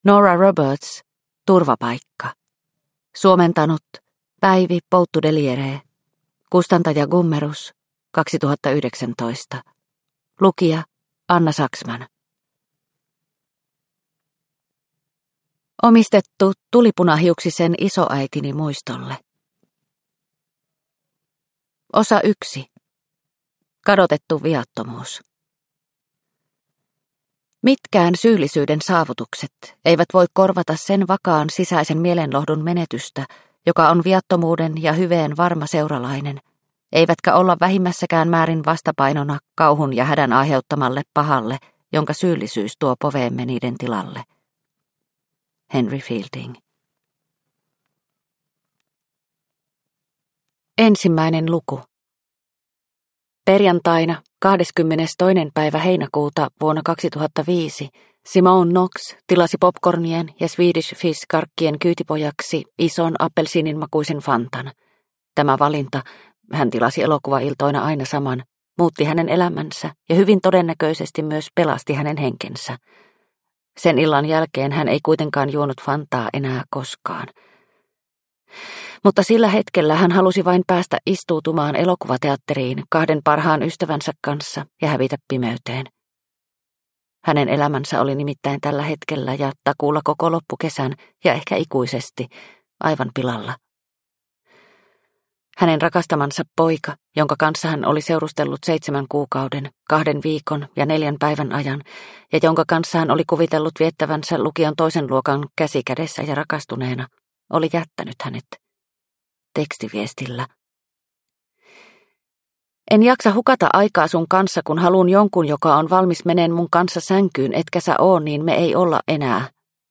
Turvapaikka (ljudbok) av Nora Roberts